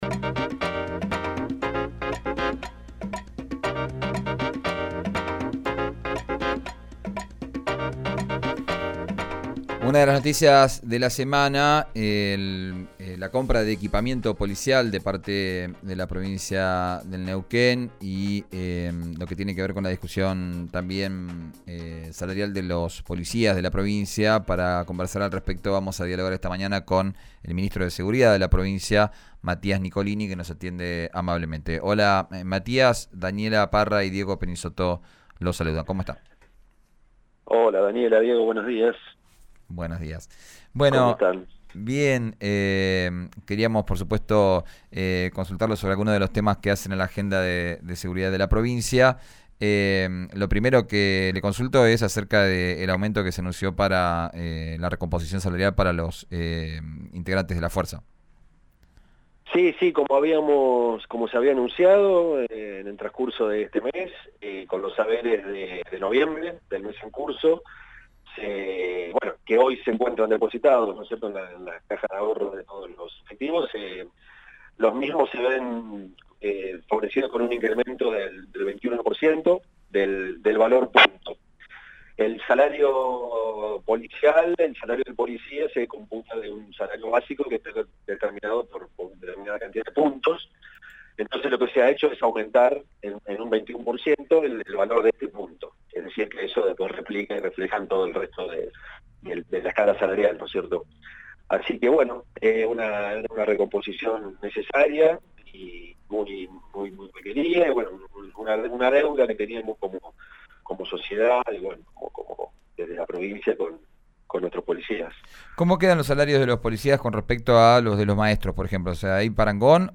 Escuchá a Matías Nicolini, ministro de Seguridad, en RÍO NEGRO RADIO